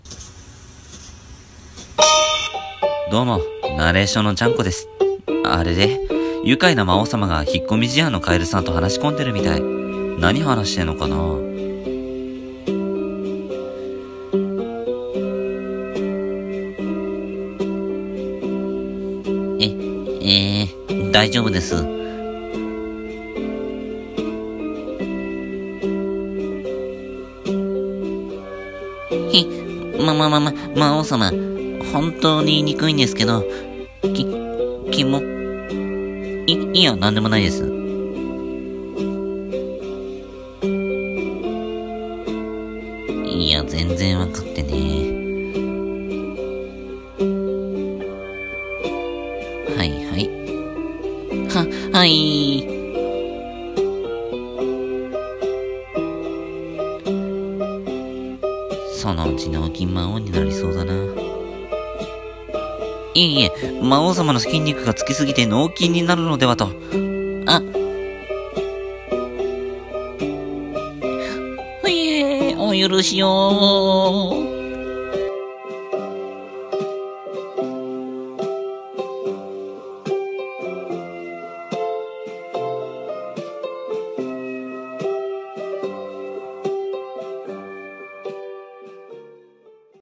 【ネタ声劇 台本】